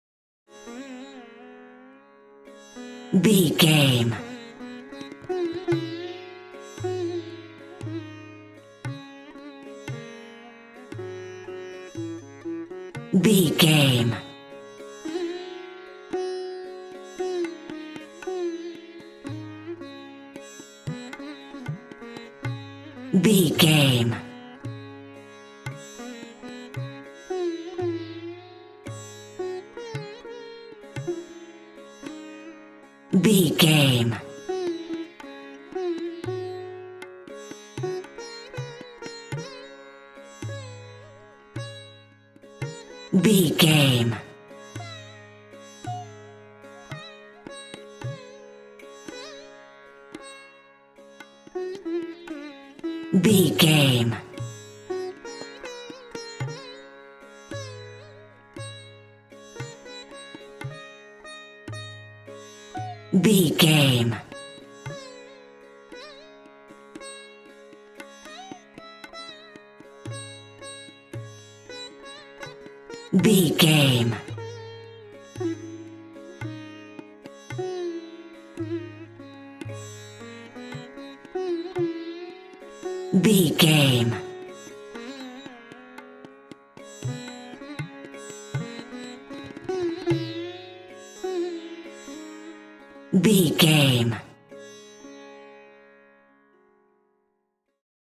Aeolian/Minor
C#
World Music